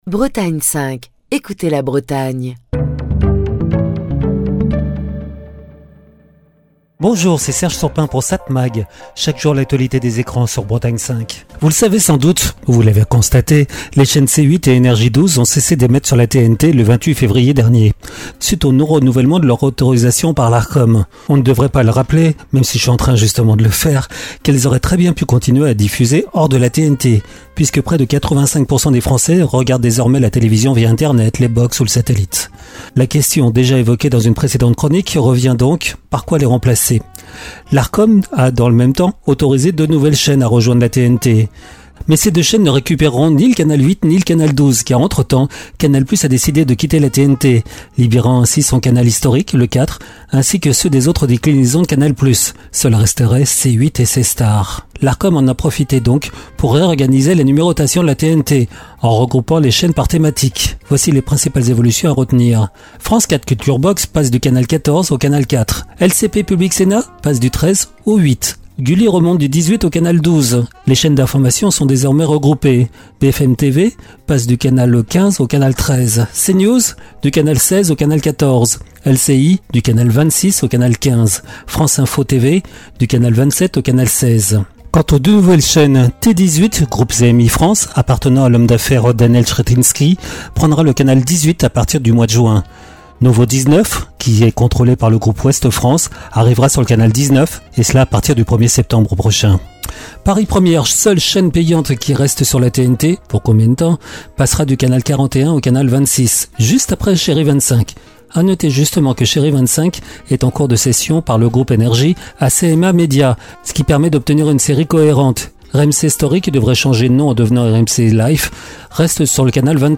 Chronique du 14 mai 2025.